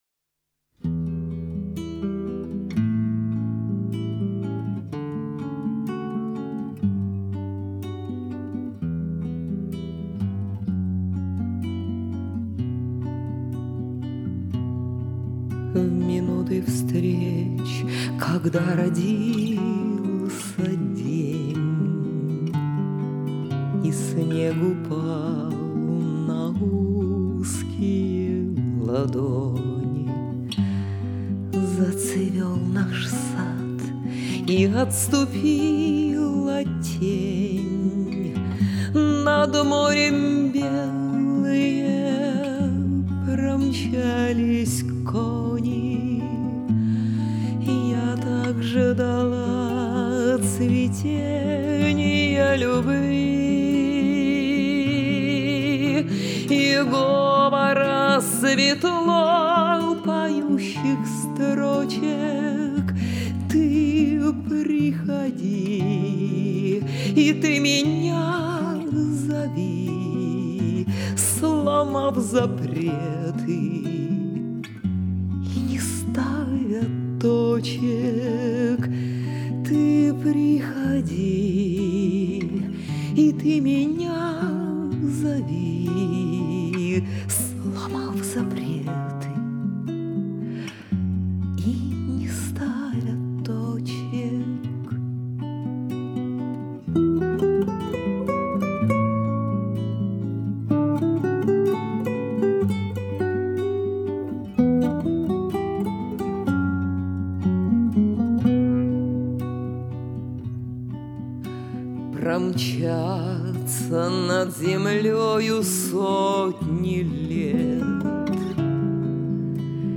Поет